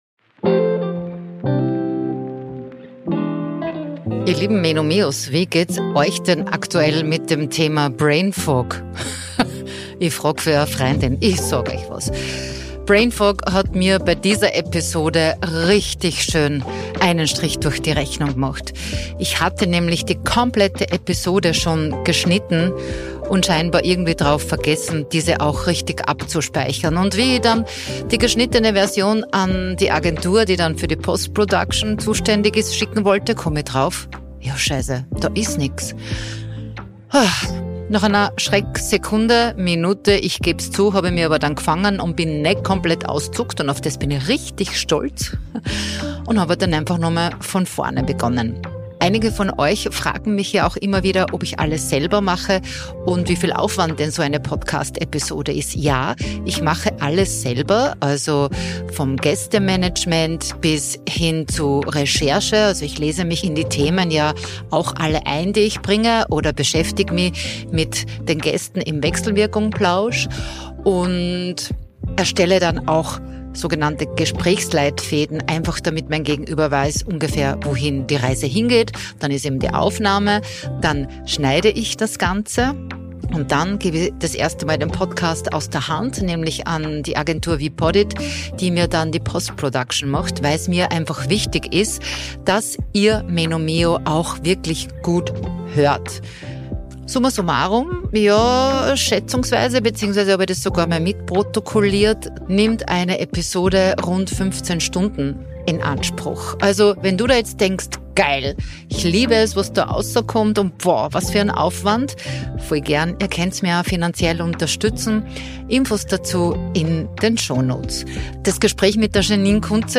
Ein Gespräch über Abgrenzung, Leichtigkeit, Älterwerden in der Öffentlichkeit – und den Mut, Hilfe anzunehmen.